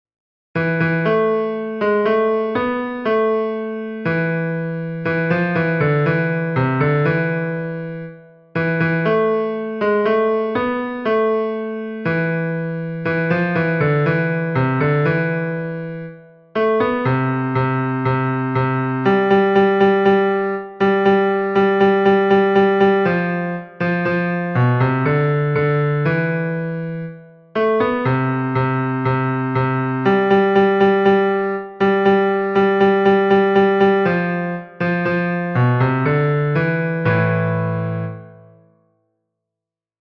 Basses